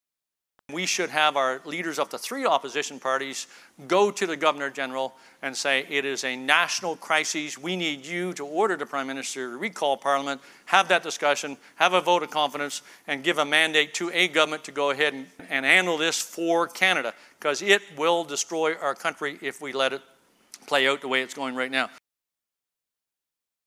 Canadian Armed Forces Retired General Rick Hillier was the keynote speaker at the Saskatchewan Crops Conference in Saskatoon on Tuesday.